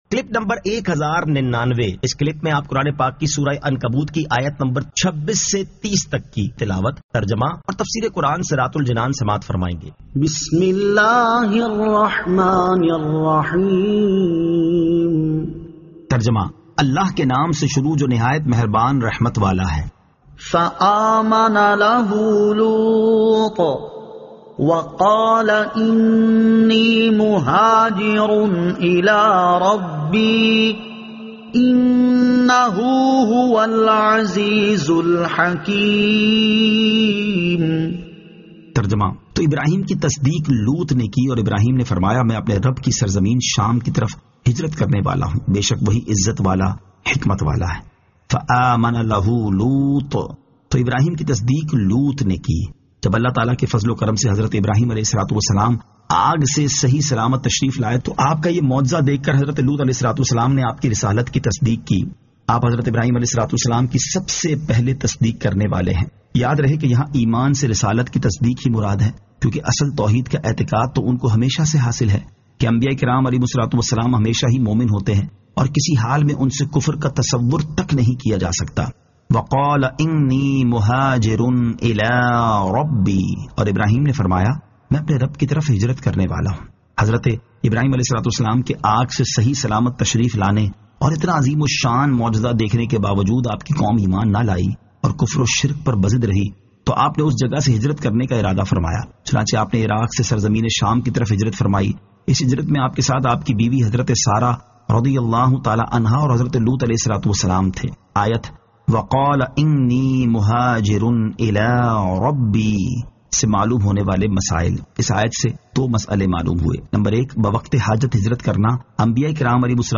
Surah Al-Ankabut 26 To 30 Tilawat , Tarjama , Tafseer
2022 MP3 MP4 MP4 Share سُوْرَۃُ الْعَنٗکَبُوت آیت 26 تا 30 تلاوت ، ترجمہ ، تفسیر ۔